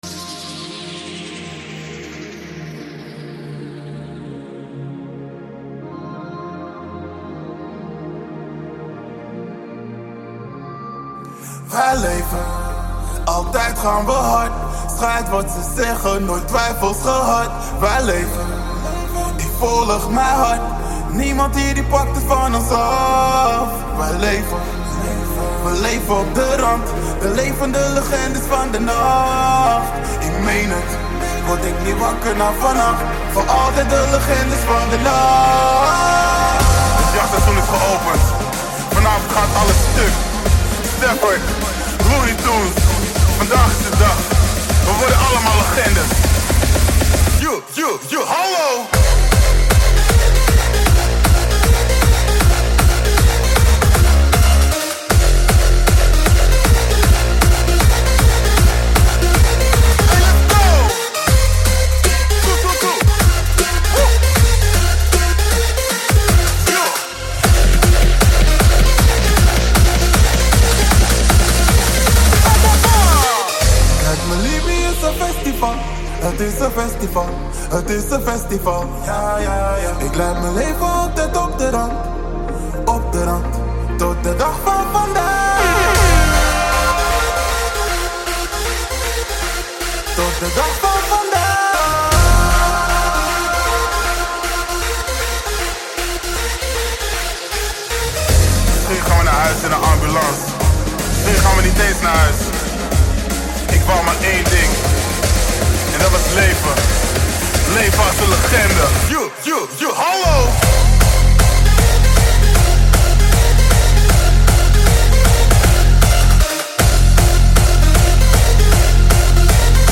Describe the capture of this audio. Quarantine Livestreams Genre: Hardstyle